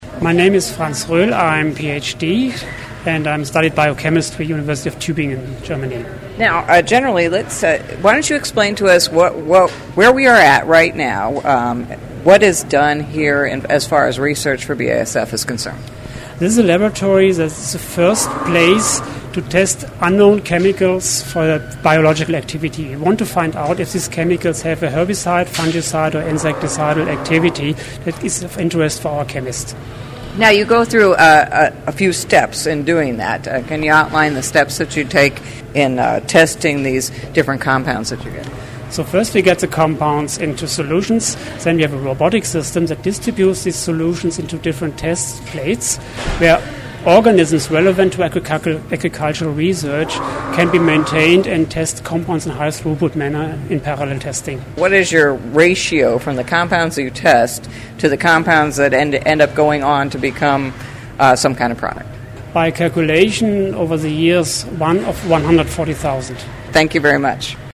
BASF research facility in Limburgerhof, Germany
interview